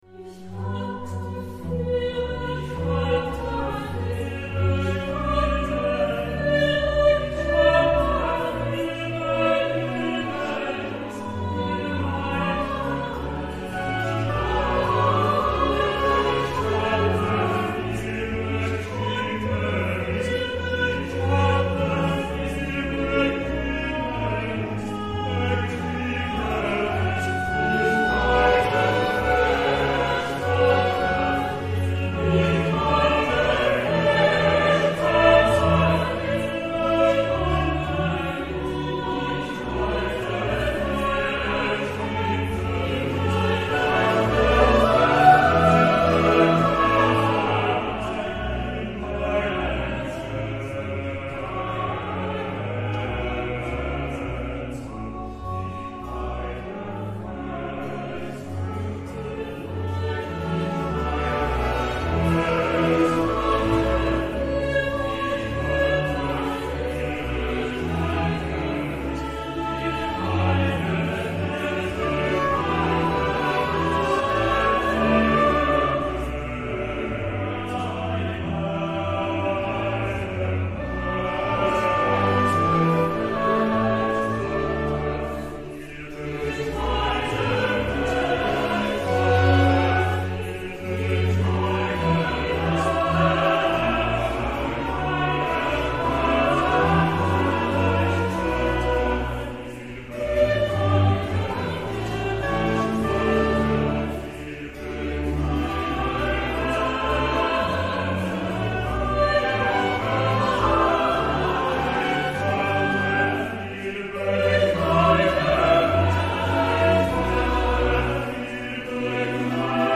BACH, cantate bwv 21 Ich hatte viel Bekummernis, 2 choeur - VERONESE, onction de David old.mp3